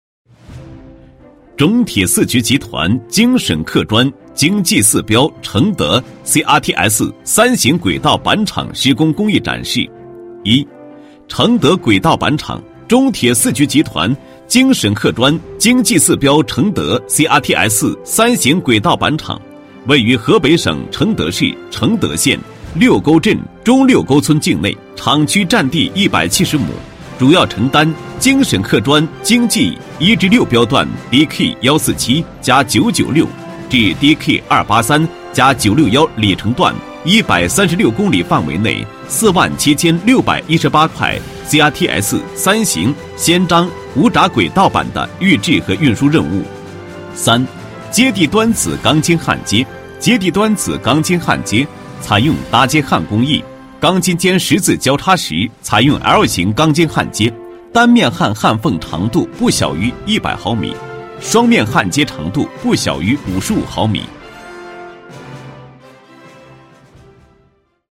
专注高端配音，拒绝ai合成声音，高端真人配音认准传音配音
男19